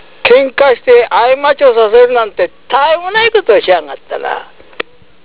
方言